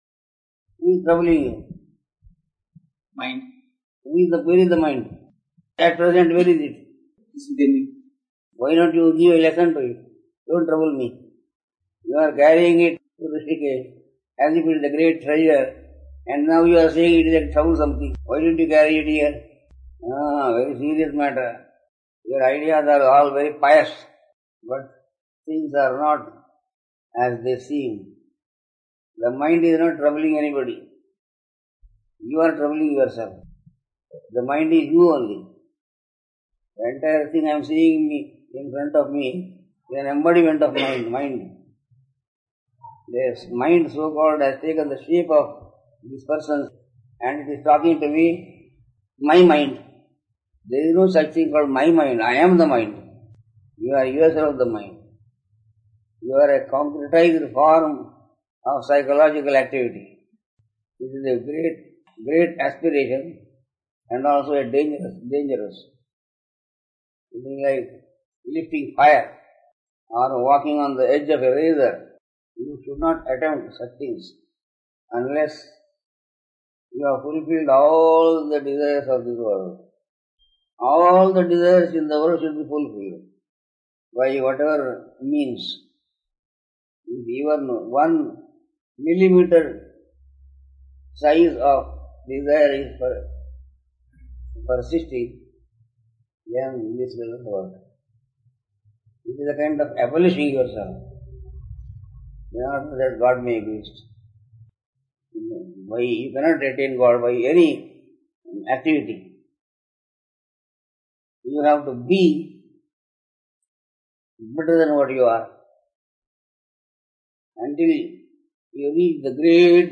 (Darshan given in March 1999)